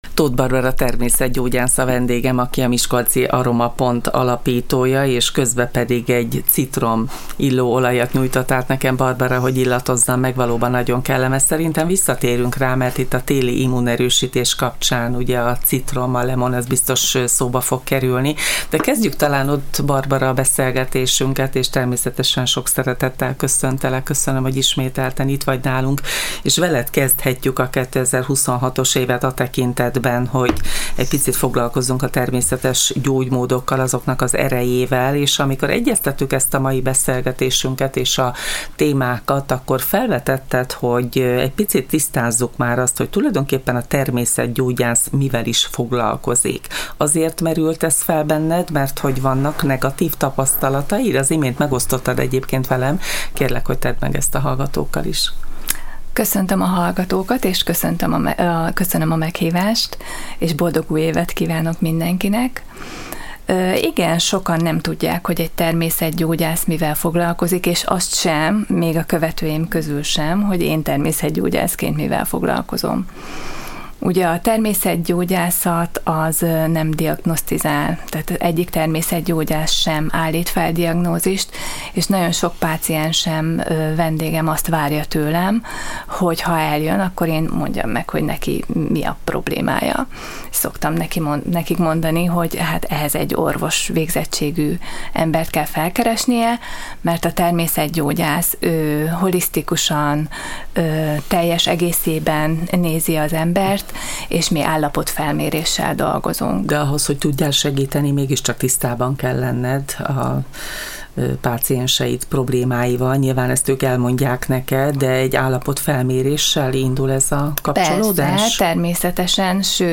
A szakember a Csillagpont Rádió stúdiójában válaszolt, miszerint a természetgyógyász nem állít fel diagnózist, egy állapotfelmérést követően javasolt holisztikus kezelési tervet, amely által a problémák okát próbálja megszüntetni.